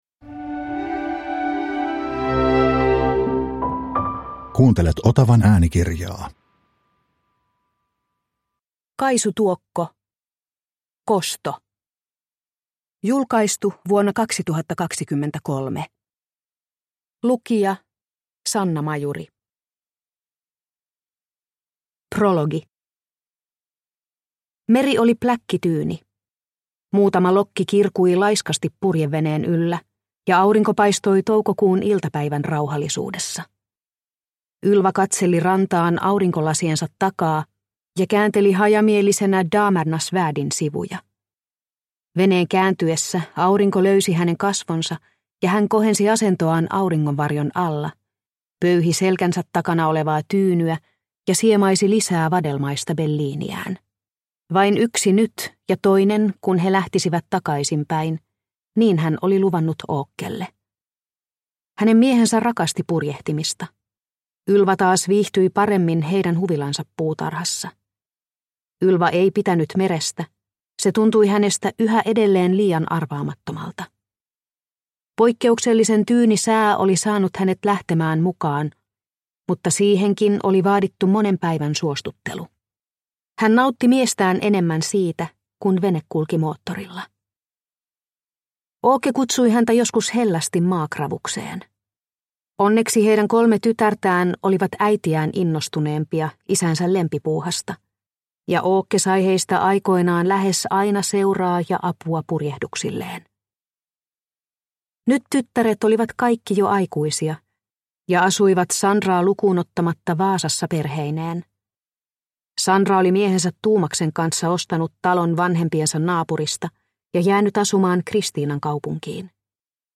Kosto – Ljudbok – Laddas ner